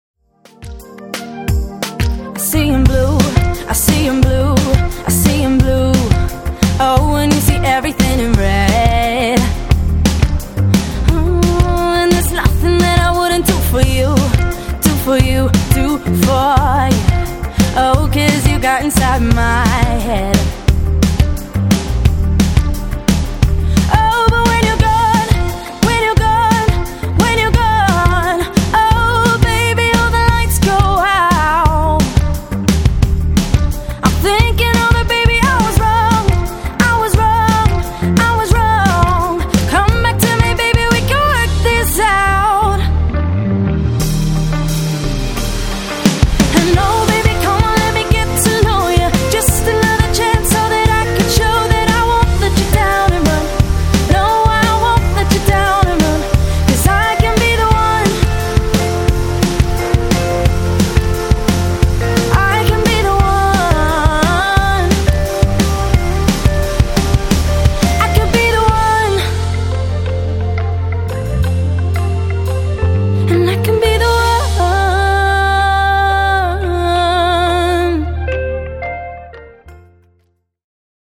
Contemporary Female Fronted Function Band